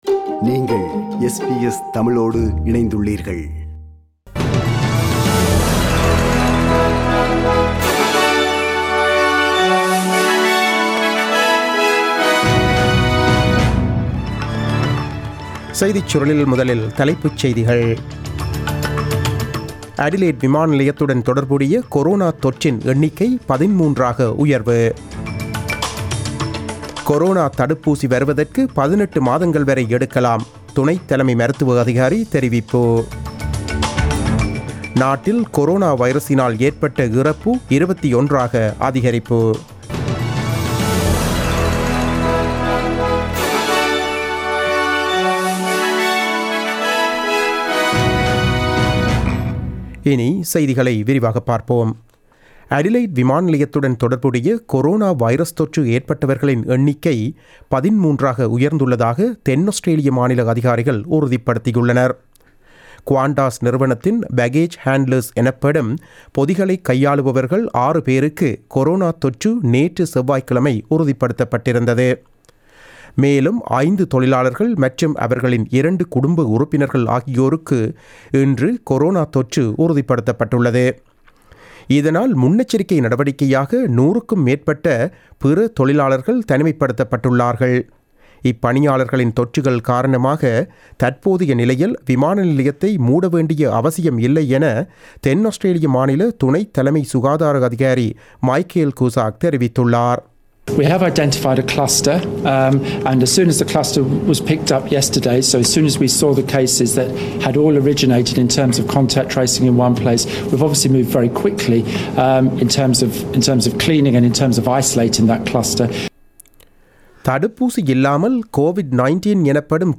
The news bulletin broadcasted on 01 April 2020 at 8pm.